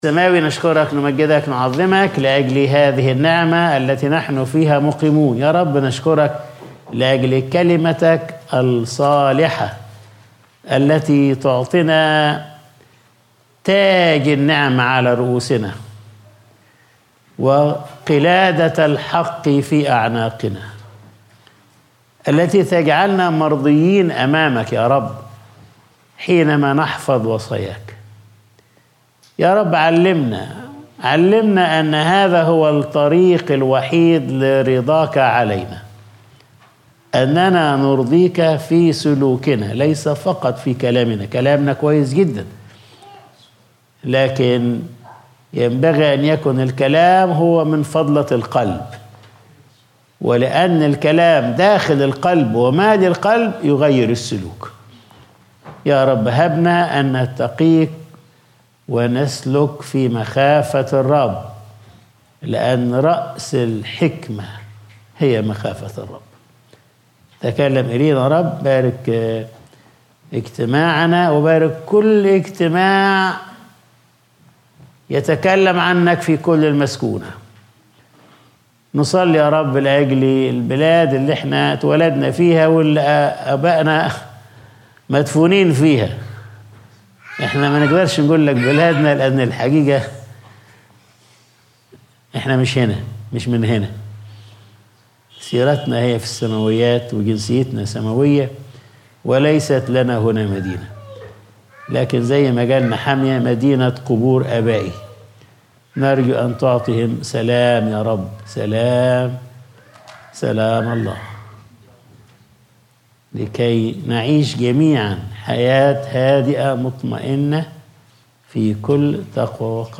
Sunday Service | يا ابني لا تنسى شريعتي